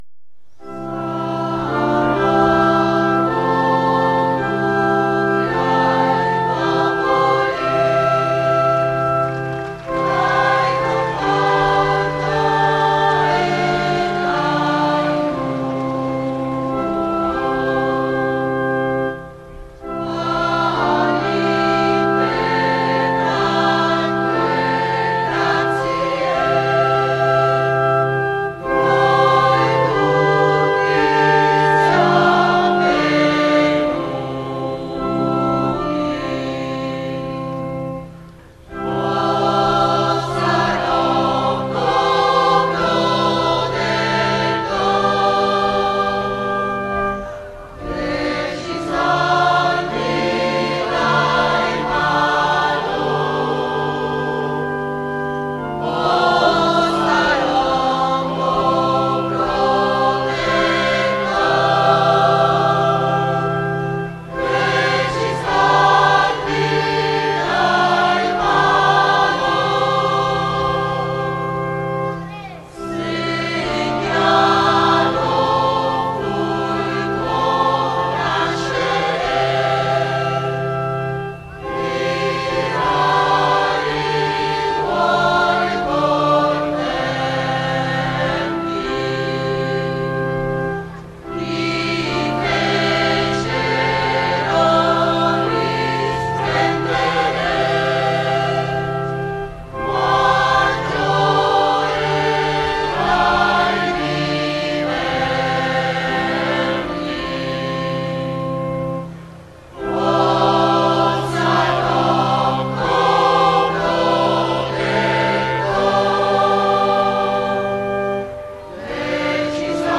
San Rocco Protettore - Scola Cantorum.mp3